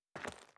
脚步
02_室外_1.wav